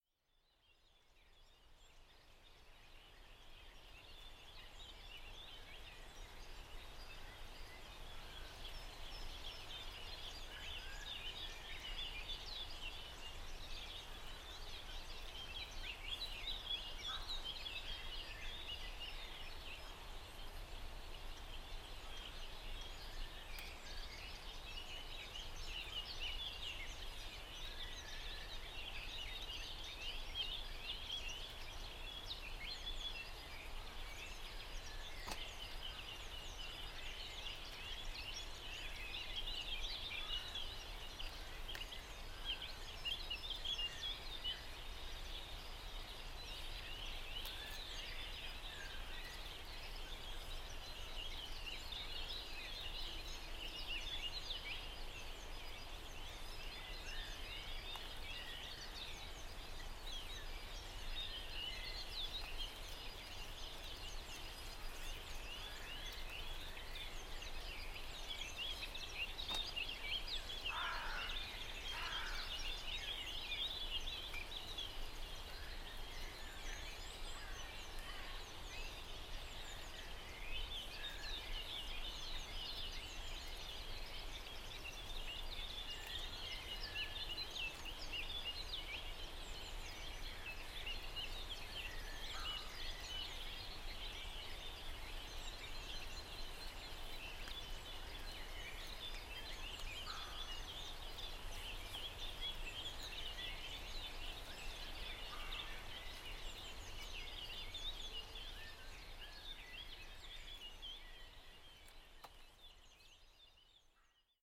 Field Recording Series by Gruenrekorder
Most of humanity’s presence is edited out of the soothing “ile de rhinau” where birds reign supreme.